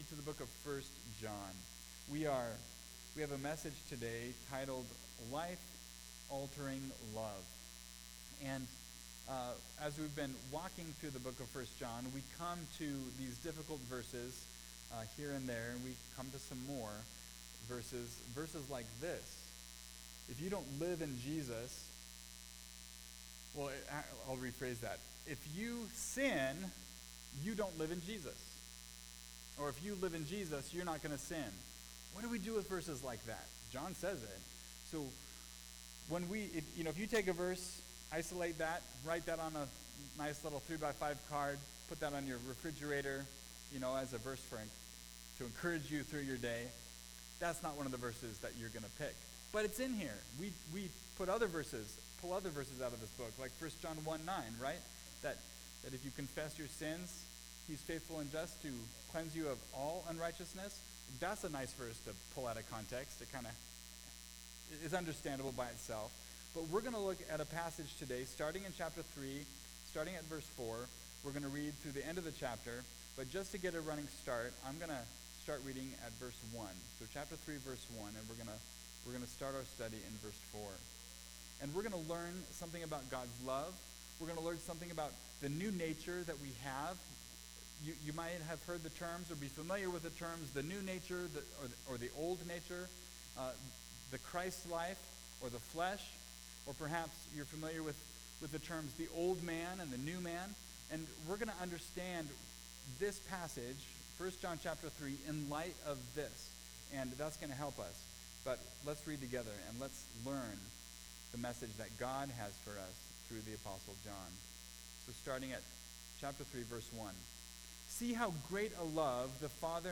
Life-Altering-Love (1st John 3:4-23) – Mountain View Baptist Church